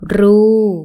– ruu